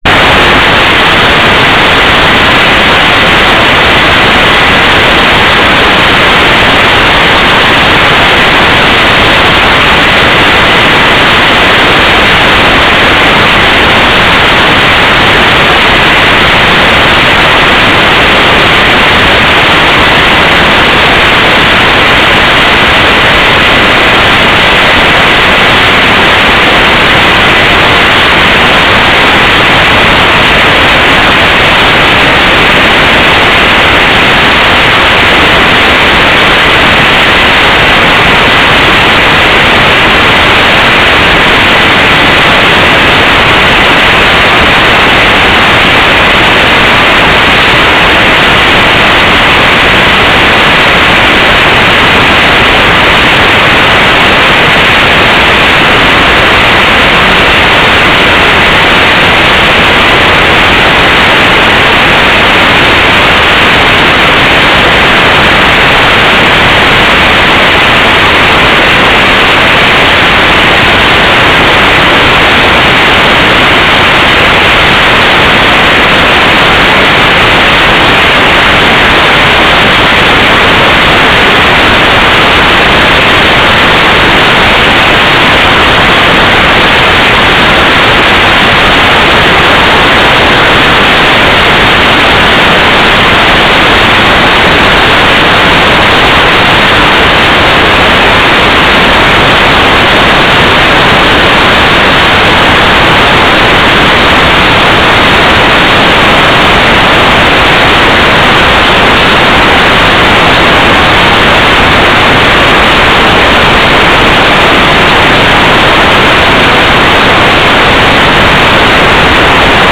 "transmitter_description": "Mode U - GMSK 4k8 AX.25 TLM",